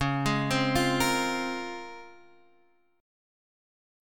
DbM13 chord